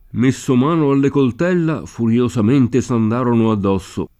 coltello [kolt$llo] s. m. — dell’uso ant. il pl. le coltella (accanto a i coltelli): messo mano alle coltella, furiosamente s’andarono addosso [